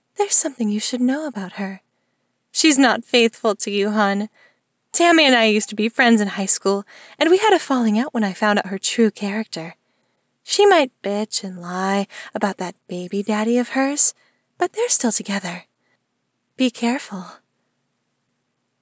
Do you all think this is "too much" echo for ACX standards?
Still using my phone as my mic. So I recorded about an hour of audio, only to listen back and think that it’s both echo-y and the room tone is a rather loud “blowing” noise.
Unedited
That’s recording in a large empty room with wooden floors and a waterfall.
It does sound more like a waterfall than a gentle rain shower.